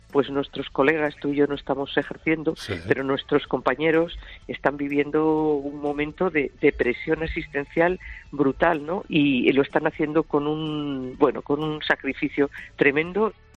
Declaraciones de Ana Pastor en COPE sobre su estado de salud, tras dar positivos por coronavirus